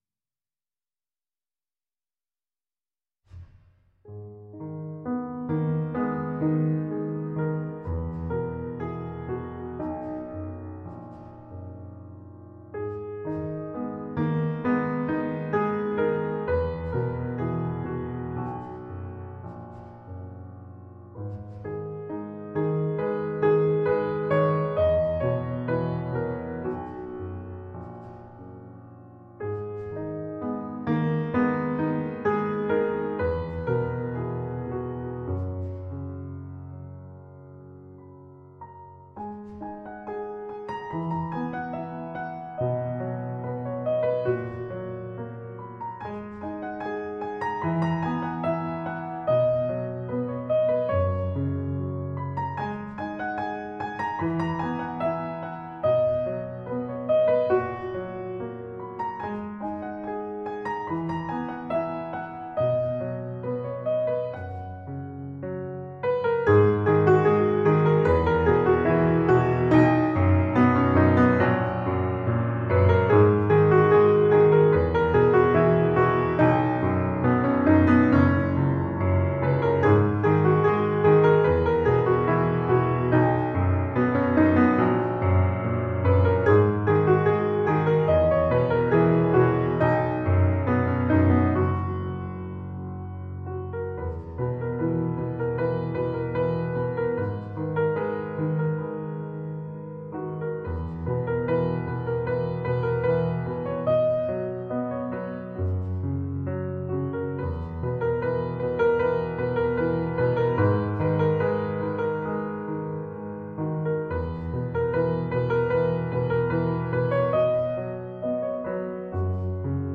Emotional piano explorations.